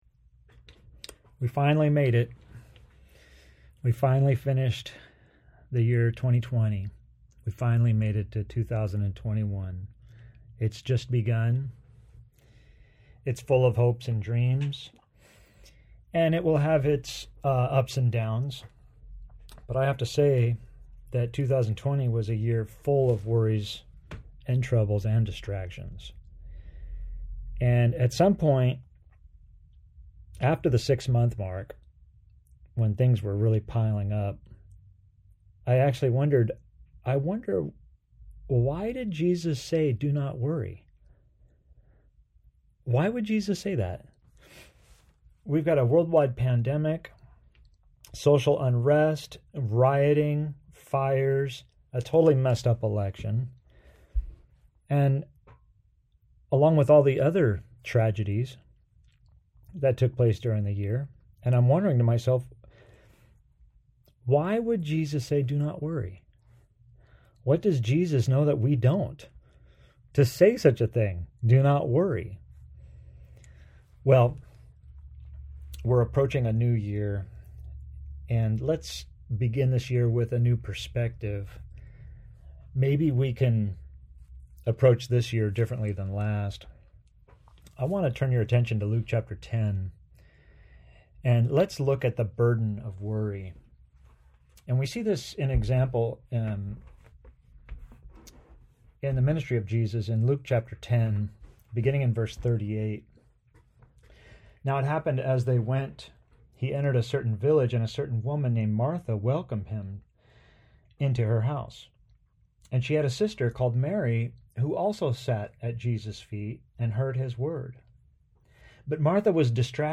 Sermon pre-recorded for Sunday Jan. 3, 2021 AUDIO | TEXT PDF Only One Thing Necessary Share this: Share on X (Opens in new window) X Share on Facebook (Opens in new window) Facebook Like Loading...